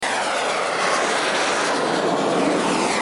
rocket_fly.mp3